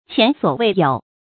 注音：ㄑㄧㄢˊ ㄙㄨㄛˇ ㄨㄟˋ ㄧㄡˇ